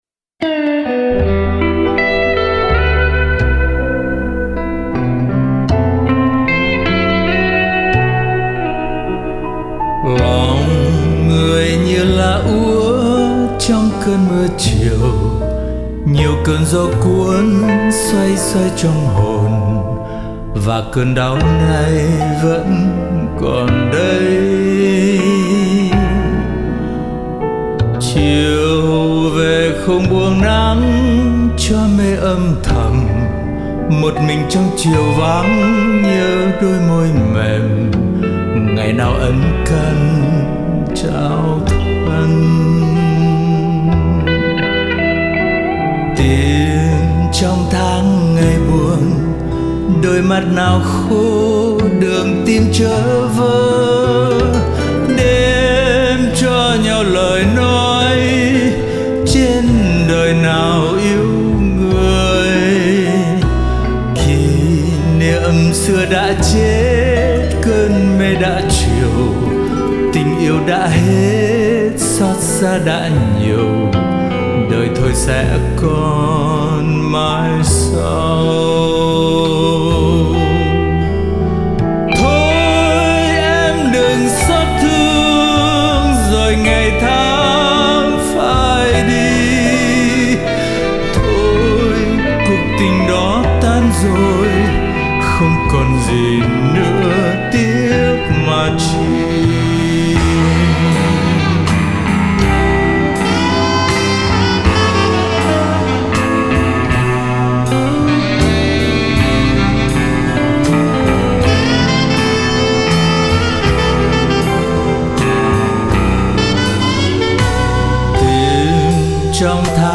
Giọng hát rất hay và truyền cảm!